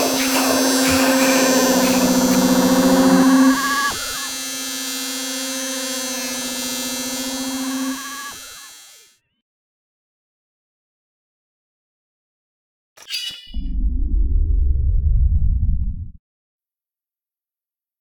sound effects.
shock.ogg